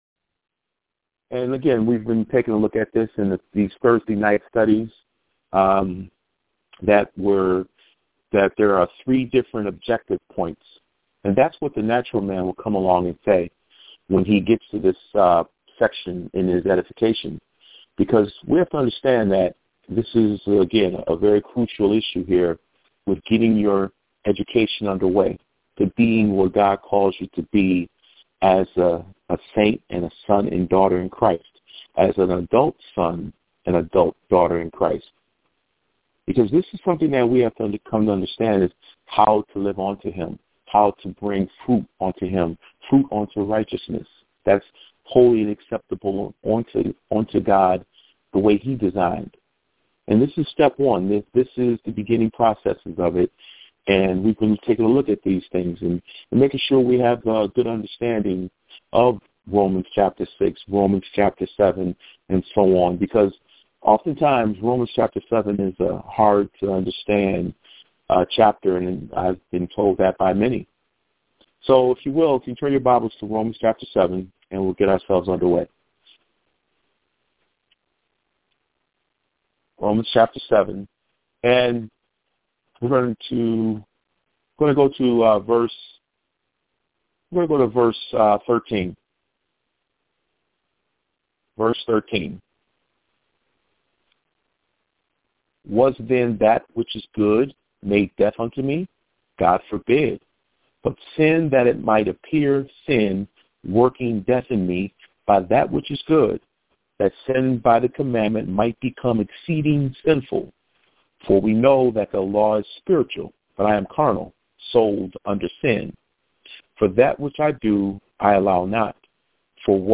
Weekly Phone Bible Studies